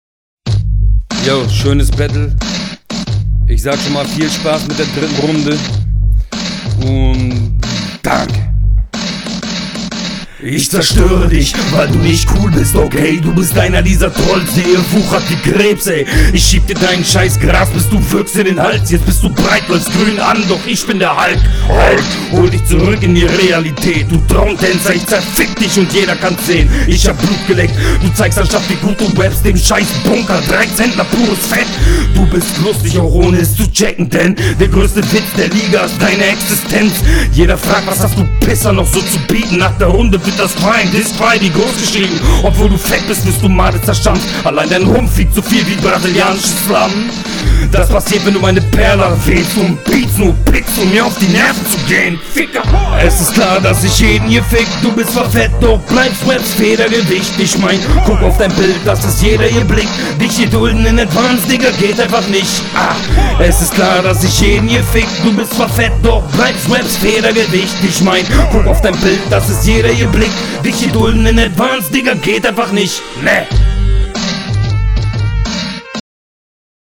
Dein Beatgeschmack sagt mir mehr zu, Nostalgie!